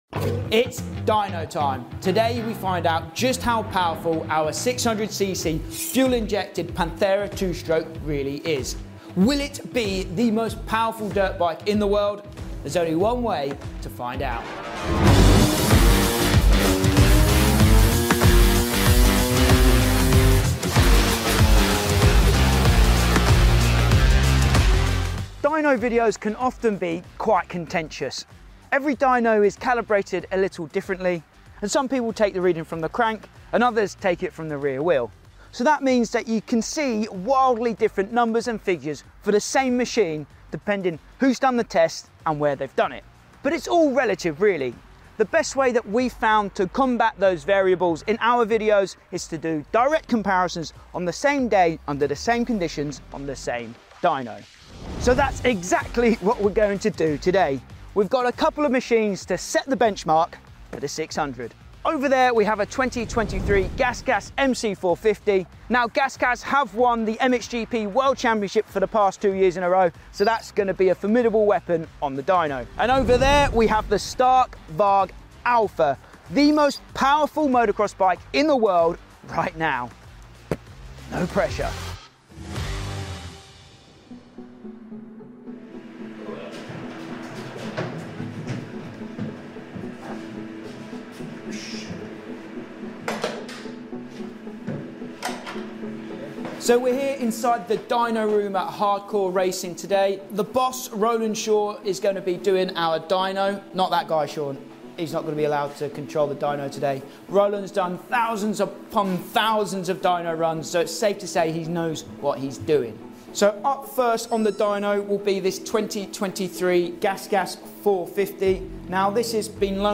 World's Most Powerful Dirt Bike vs Insane 600cc 2 Stroke